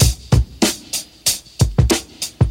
• 96 Bpm Fresh Breakbeat Sample B Key.wav
Free breakbeat - kick tuned to the B note. Loudest frequency: 2791Hz
96-bpm-fresh-breakbeat-sample-b-key-2I6.wav